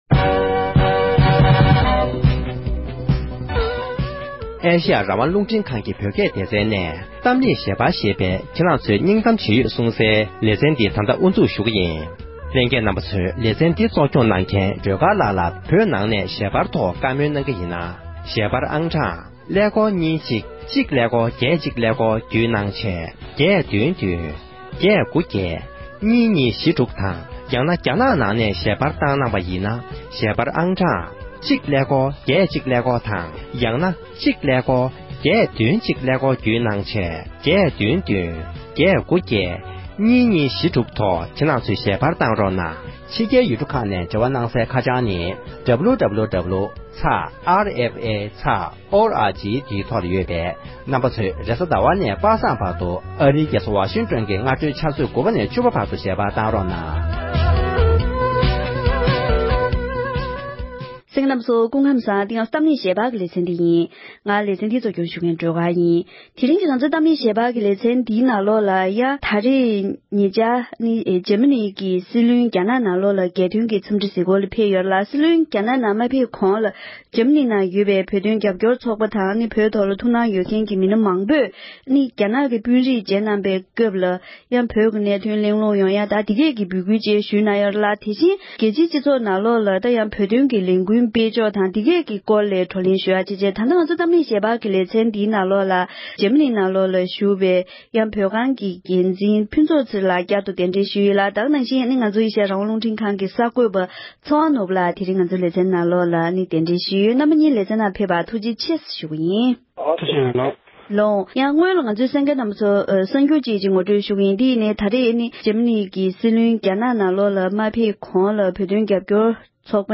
༄༅༎ཐེངས་འདིའི་གཏམ་གླེང་ཞལ་པར་ཞེས་པའི་ལེ་ཚན་ནང་དུ། འཇར་མ་ནིའི་སྲིད་བློན་རྒྱ་ནག་ལ་རྒྱལ་དོན་འཚམས་གཟིགས་ཕེབས་སྐབས། འཇར་མ་ནིའི་བོད་དོན་རྒྱབ་སྐྱོར་ཚོགས་པས་བོད་དོན་ལས་འགུལ་སྤེལ་ཡོད་པ་དང༌། དེ་བཞིན་རྒྱལ་སྤྱིའི་སྟེང་གི་བོད་དོན་ལས་འགུལ་སྐོར་ལ་བགྲོ་གླེང་གནང་བ་ཞིག་གསན་རོགས་ཞུ༎